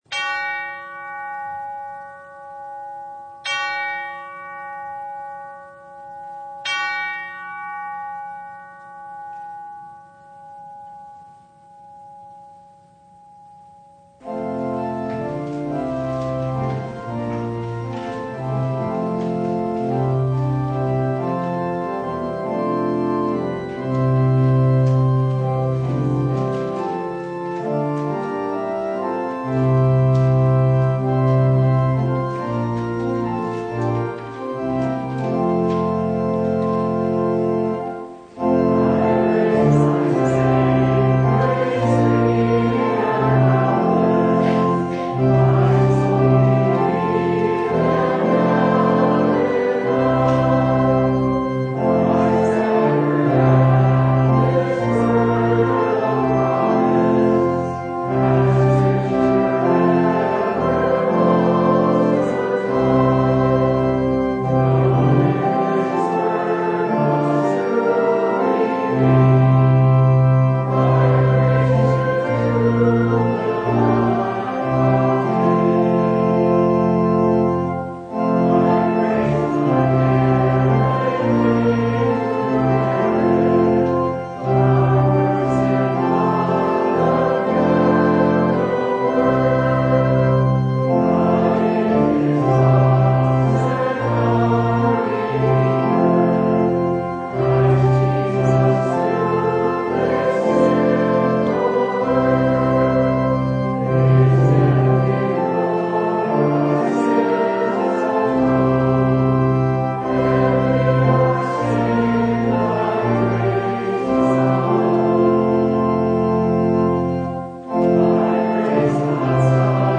Download Files Bulletin Topics: Full Service « Christian Hope – Hope While Living in the World What’s Love Got to Do with God?